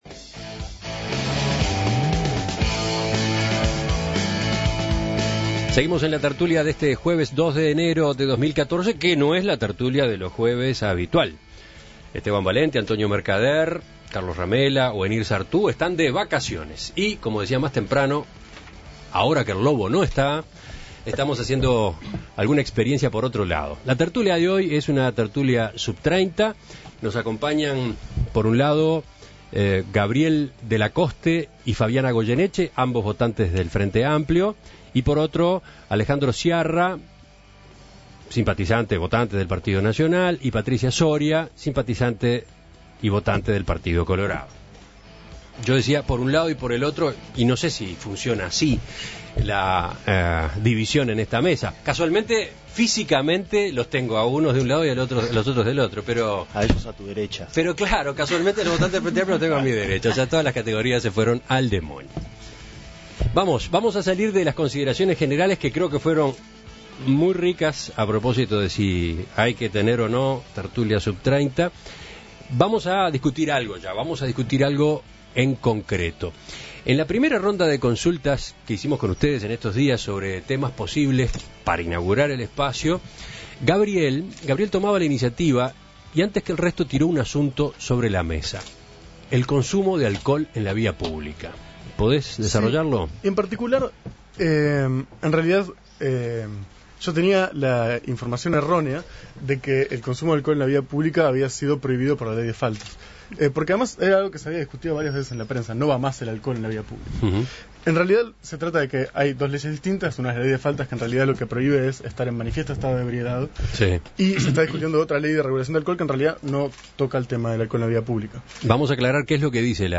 Un tema de los que preocupan generacionalmente: el consumo de alcohol en la vía pública. Compartimos los comentarios de los contertulios sub-30.